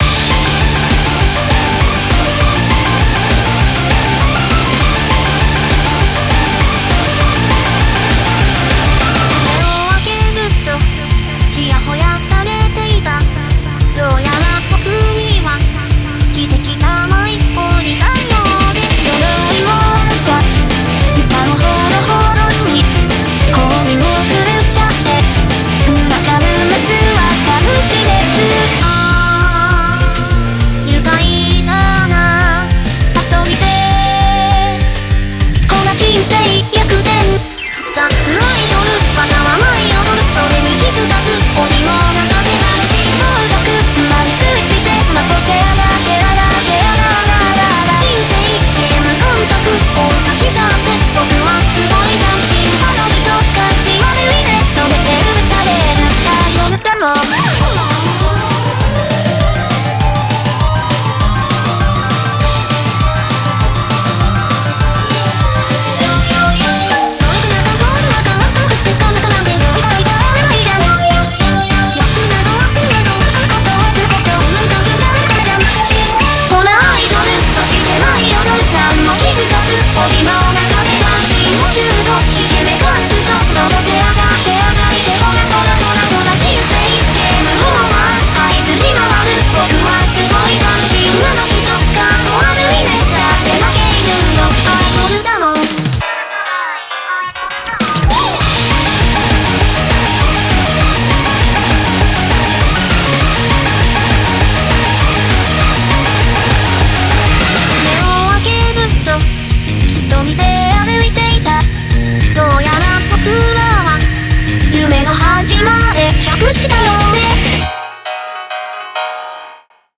BPM200
Audio QualityPerfect (High Quality)
Commentsvocaloid time i love vocaloid